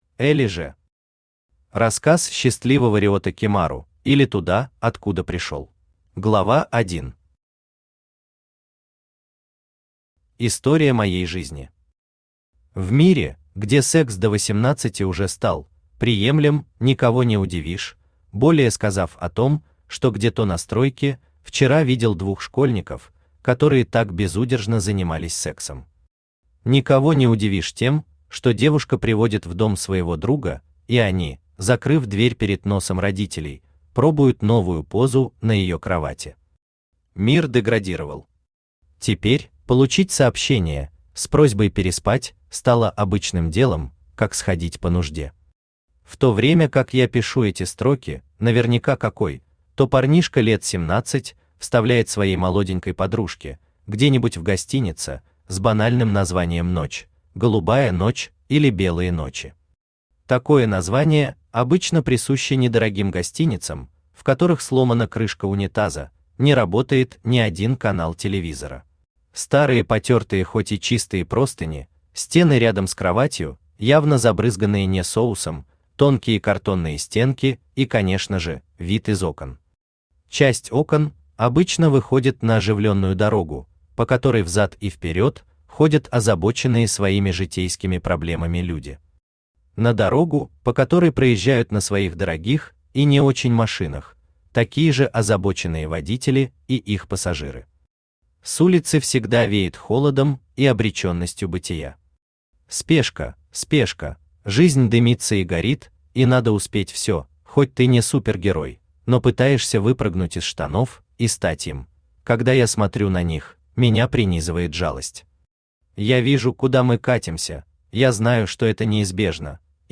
Аудиокнига Рассказ счастливого Риото Кимару, или Туда, откуда пришел | Библиотека аудиокниг